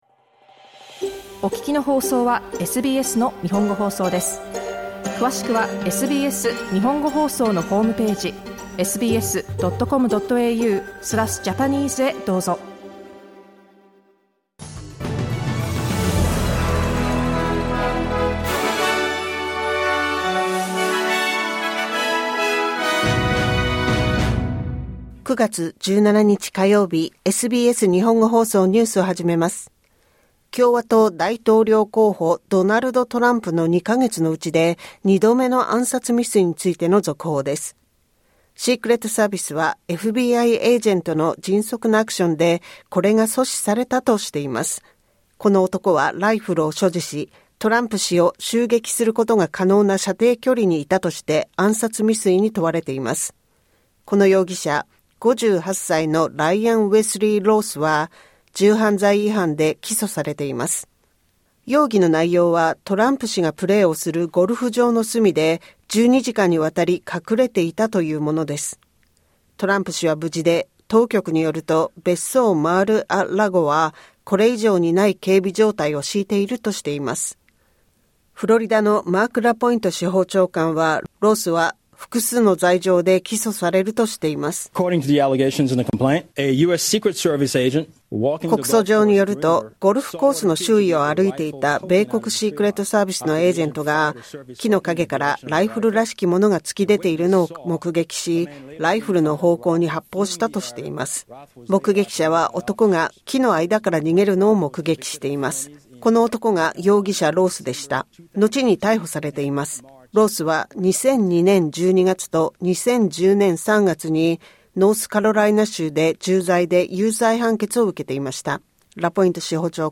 SBS日本語放送ニュース9月17日火曜日